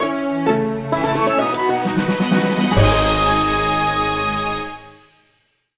theme
This is a sample from a copyrighted musical recording.